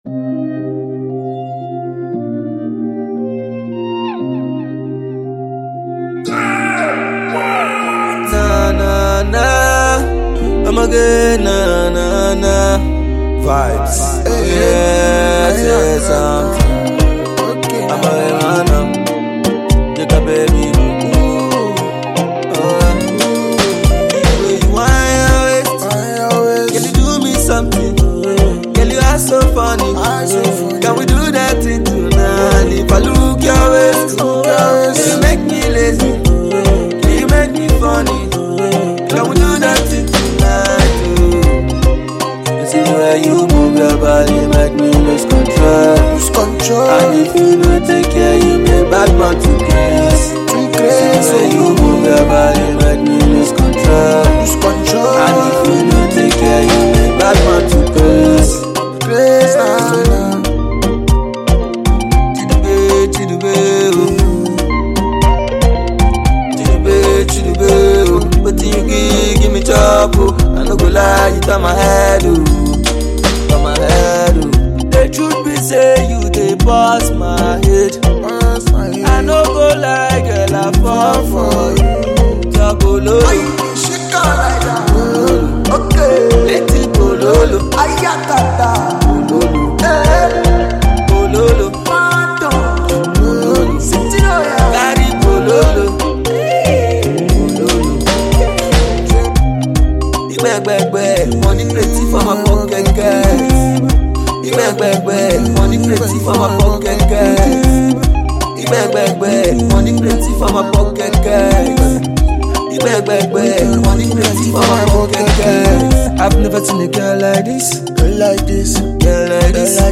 This new record is a music with good sound and lovely melody